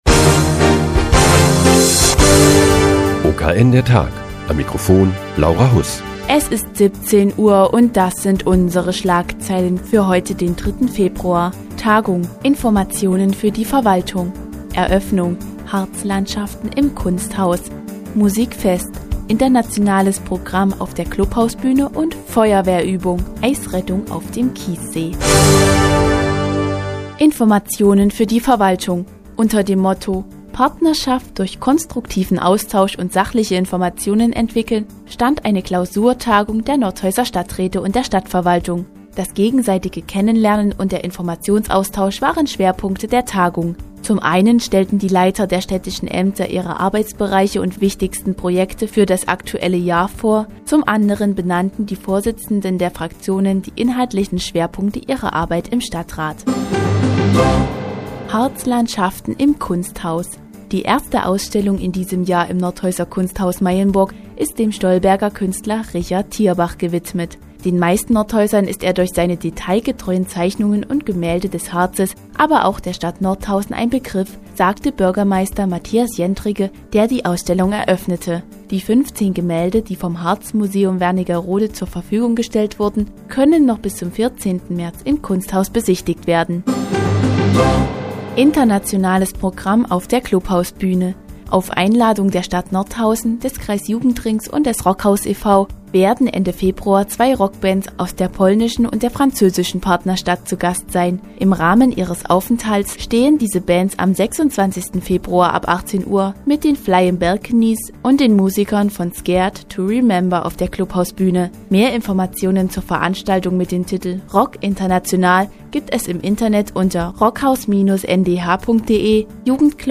Die tägliche Nachrichtensendung des OKN ist nun auch in der nnz zu hören. Heute geht es um die Ausstellung "Harzlandschaften" im Kunsthaus Meyenburg und die Veranstaltung "Rock International" im Jugendclubhaus.